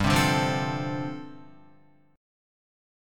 G9sus4 chord